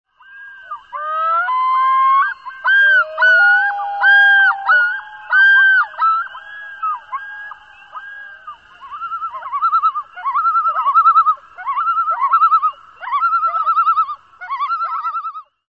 Background sound: Common Loon
Loon.mp3